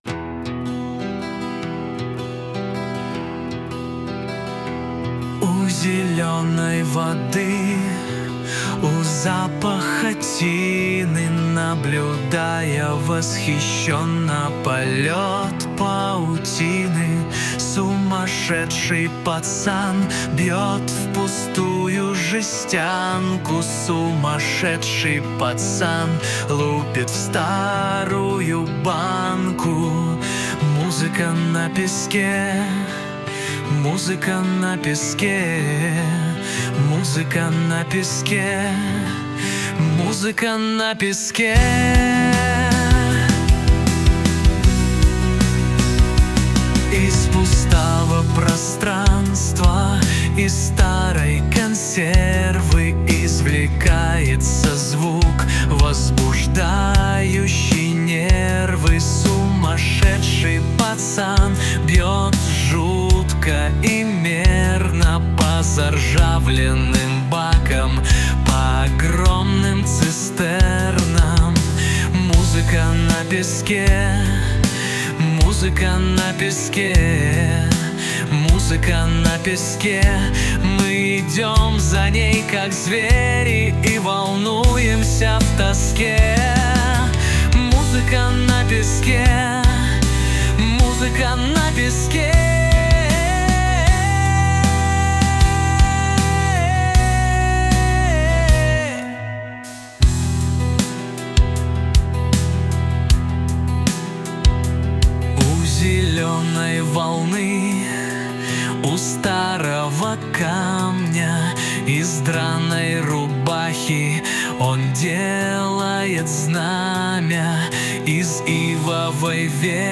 RUS, Romantic, Lyric, Rock, Indie | 03.04.2025 20:52